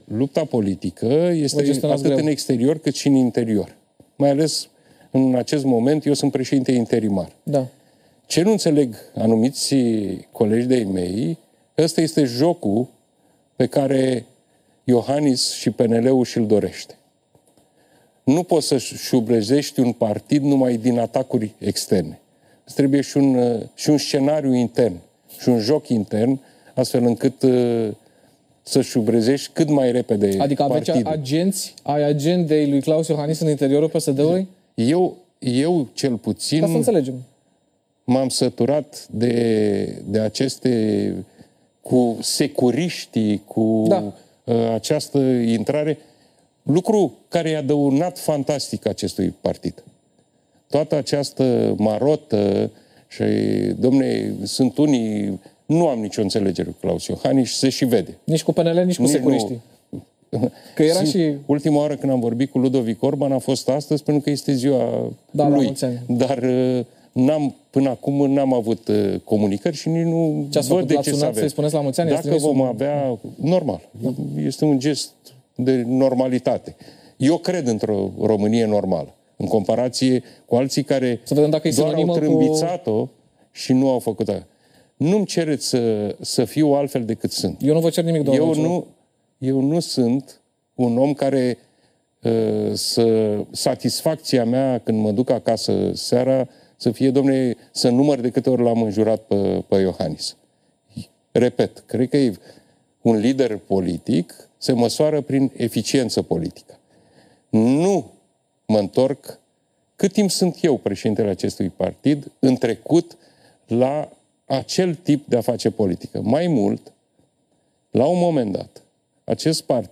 ”Marea majoritate a colegilor au susţinut că trebuie organizat un congres pentru a avea o conducere clar aleasă, care îşi va asuma acest ciclu electoral, atât locale, cât şi parlamentare (…) Consider că trebuie făcut cât mai repede un congres în PSD, imediat după terminarea stării de alertă”, a mai declarat Marcal Ciolacu, luni seara, la televiziunea publică, reafirmând că va candida în competiția internă.